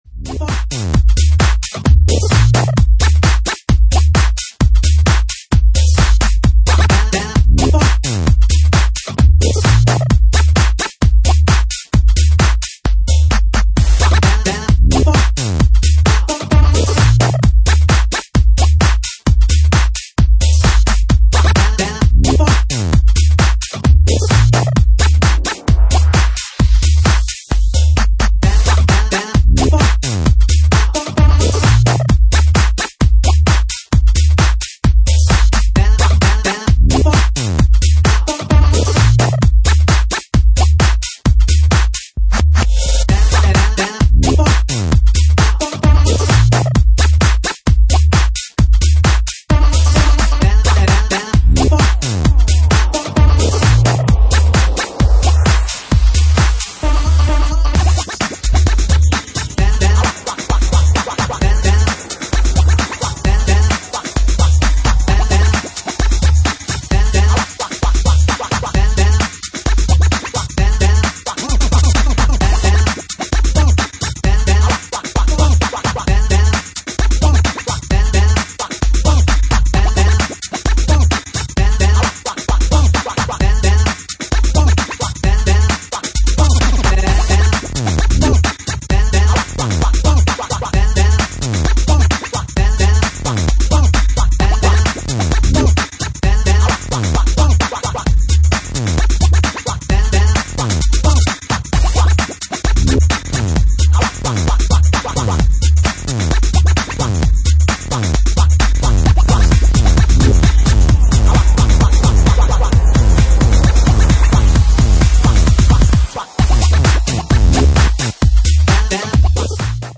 Genre:Bassline House
Bassline House at 66 bpm